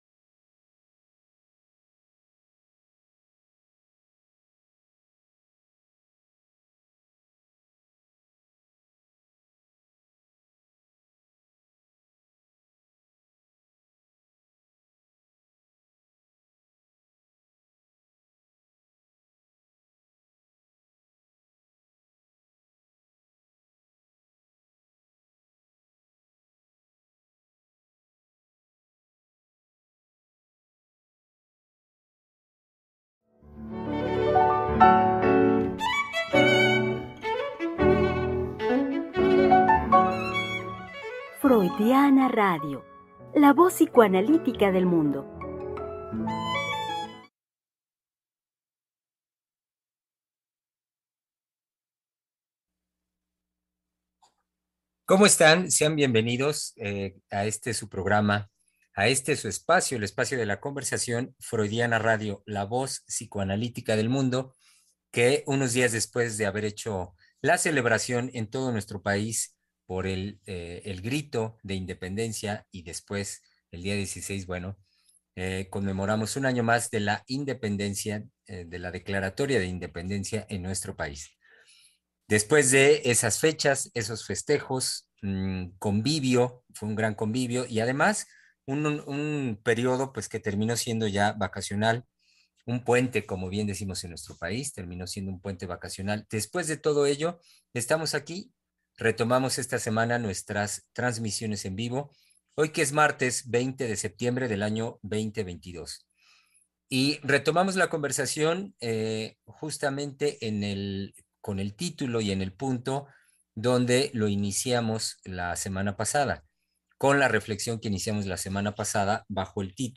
Programa transmitido el 20 de septiembre del 2022.